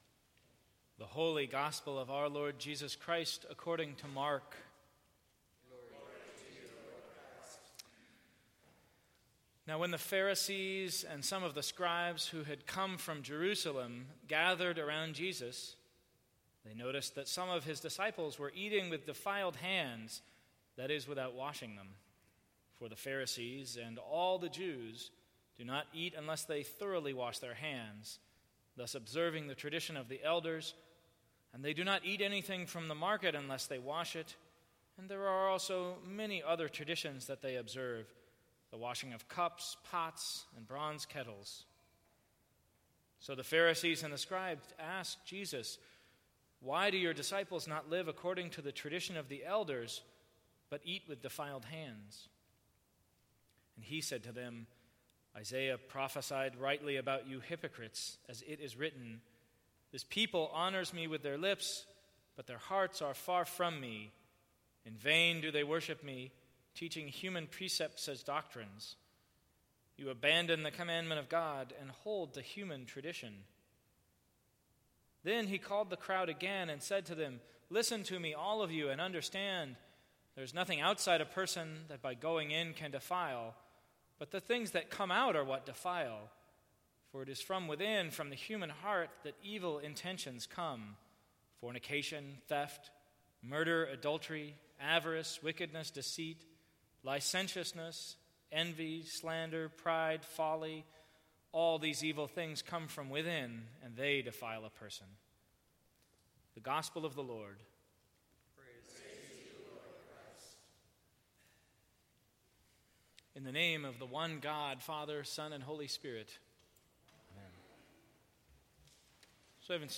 Sermons from St. Cross Episcopal Church Relationships are hard… but good advice can help!